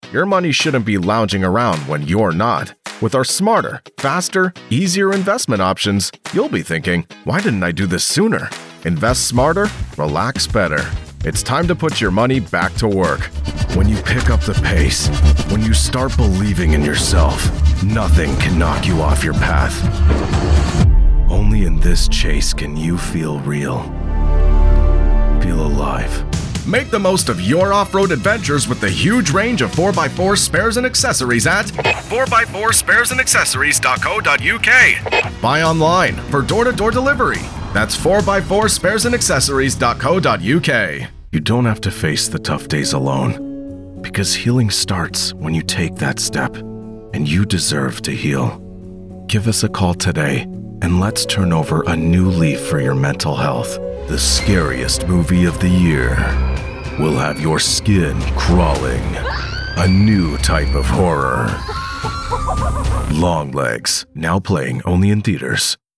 Promos
All recordings are done in my professional sound-treated vocal booth.